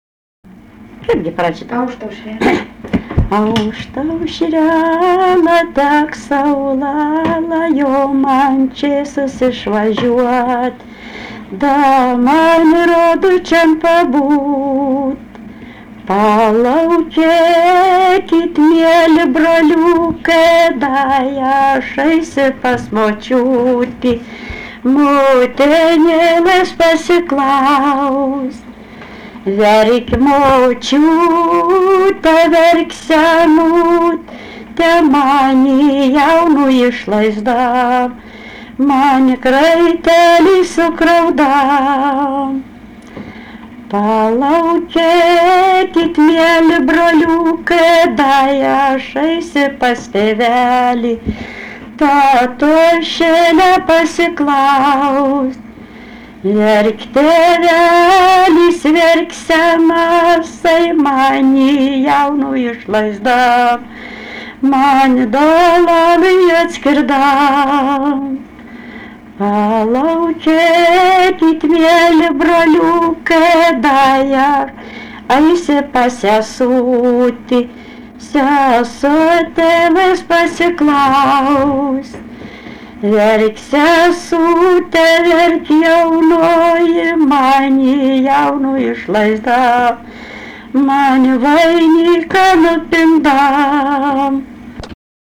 daina, vestuvių
vokalinis